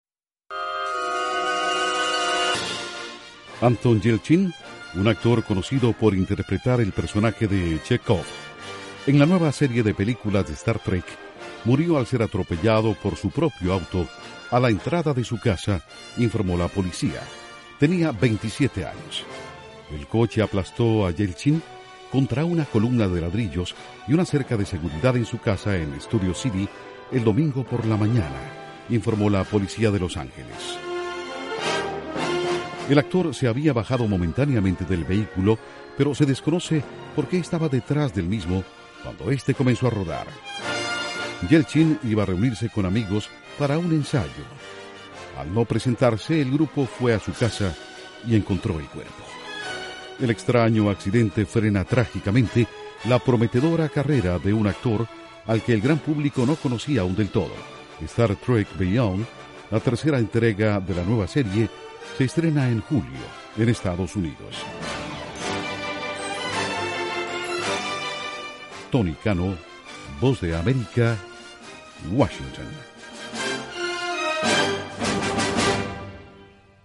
El actor Anton Yelchin de la nueva serie de películas de "Star Trek" muere a los 27 años en un accidente. Informa desde la Voz de América